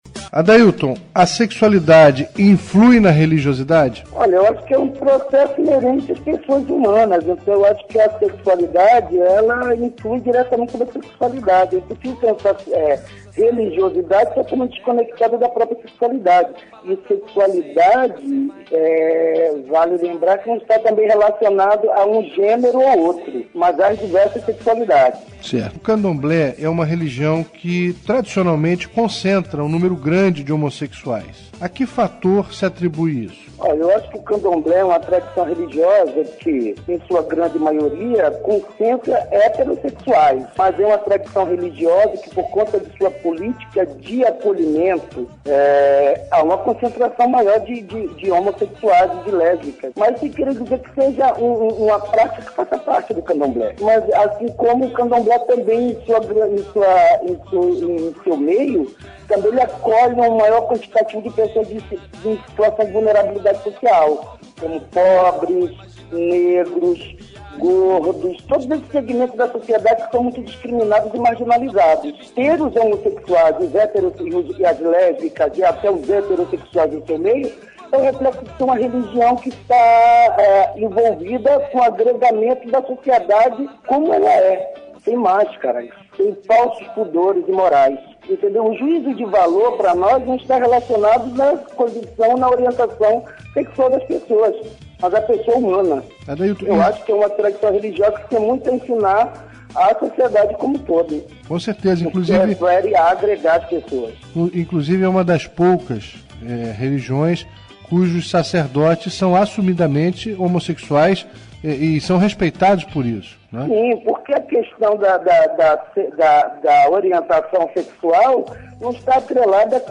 Entrevistas e Debates